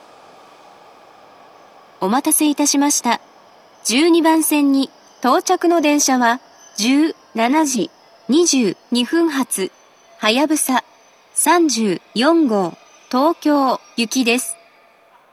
１２番線到着放送
接近放送は及び到着放送は「はやぶさ３４号　東京行」です。